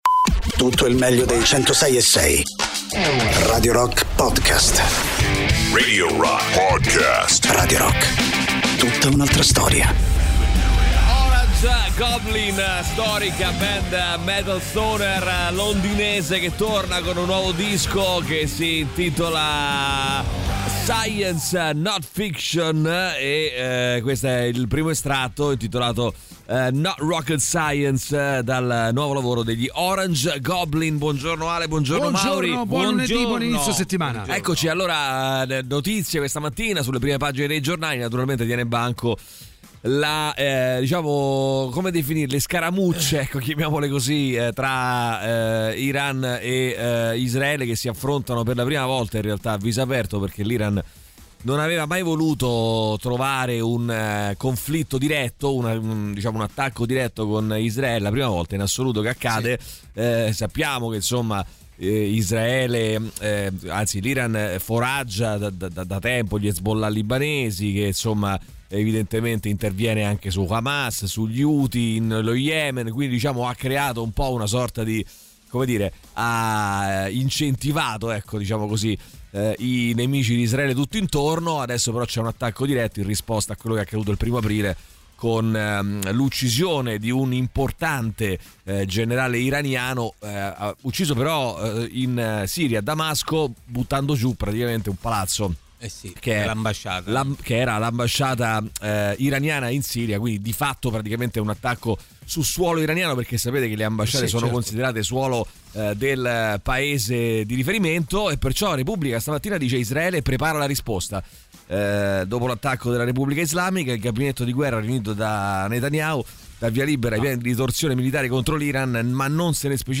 in diretta dal lunedì al venerdì dalle 6 alle 10 sui 106.6 di Radio Rock.